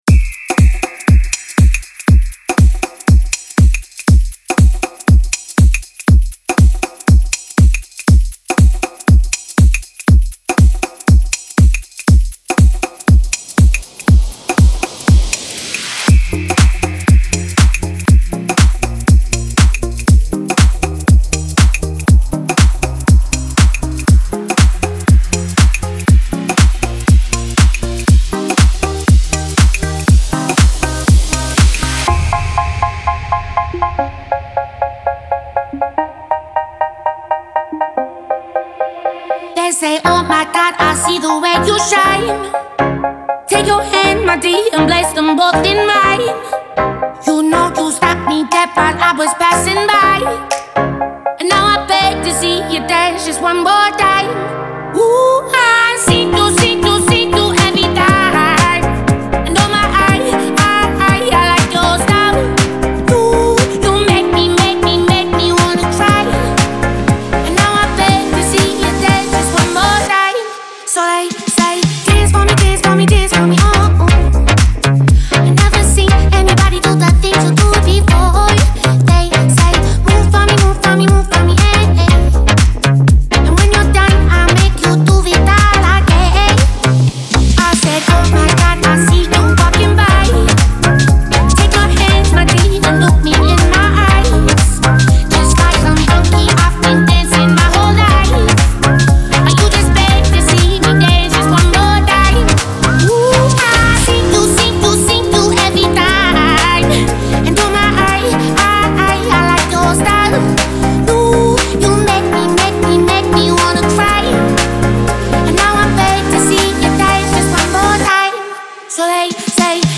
Стиль: Dance / Electronic / House / Pop